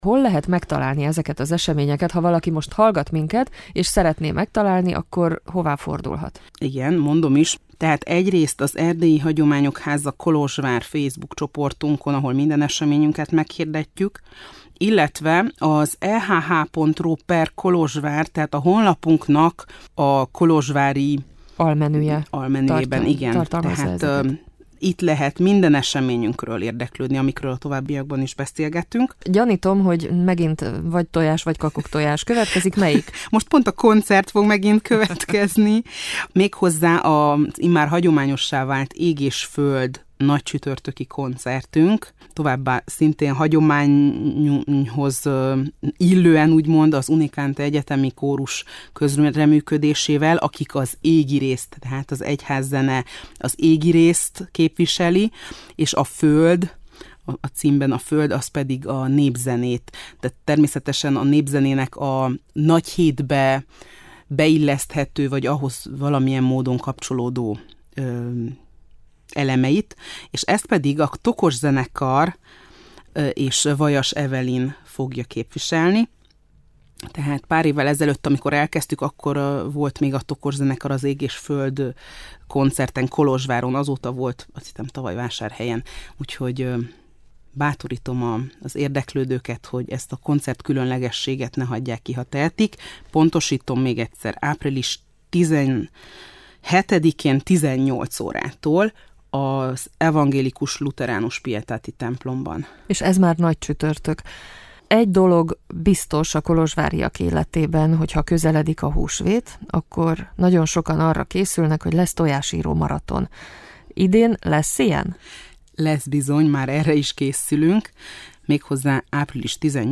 Házigazda: